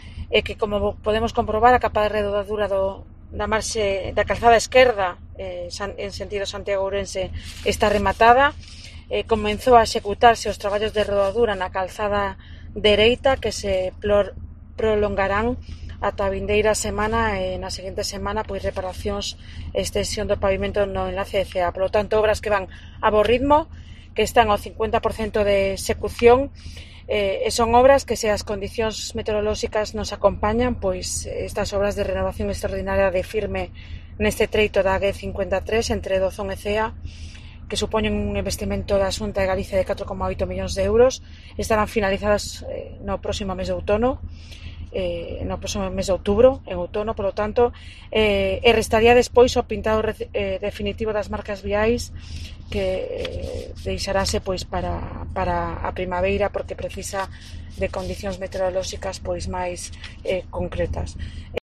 Declaraciones de Ethel Vázquez sobre las obras en la AG-53